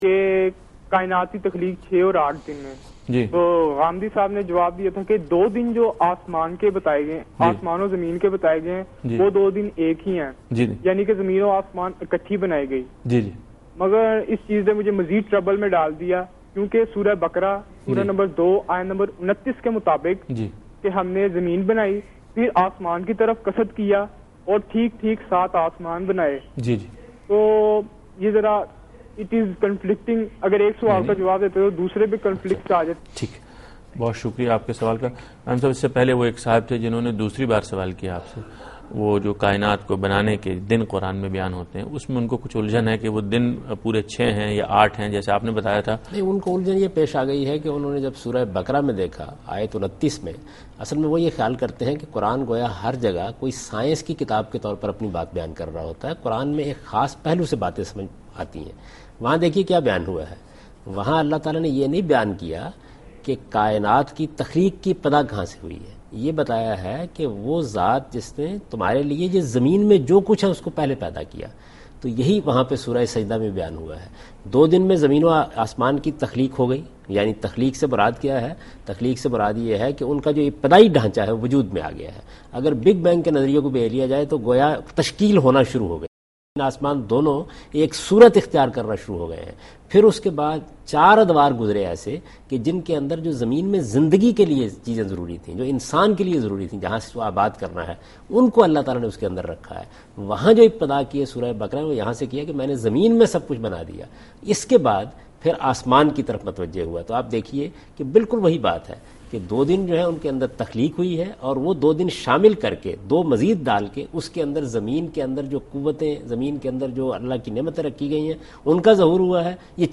Answer to a Question by Javed Ahmad Ghamidi during a talk show "Deen o Danish" on Duny News TV
دنیا نیوز کے پروگرام دین و دانش میں جاوید احمد غامدی ”زمین و آسمان کی تخلیق کا دورانیہ “ سے متعلق ایک سوال کا جواب دے رہے ہیں